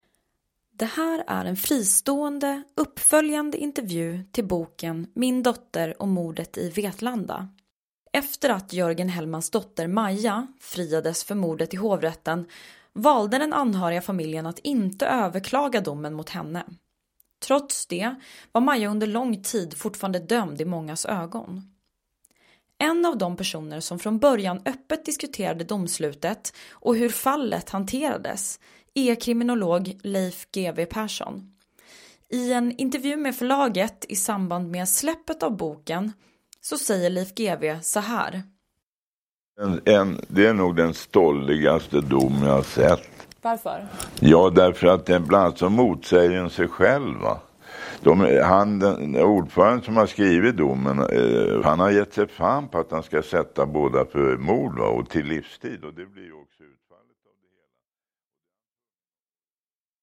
I den här fristående intervjun med förlaget, i samband med släppet av boken Min dotter och mordet i Vetlanda, berättar han hur han ser på fallet i dag.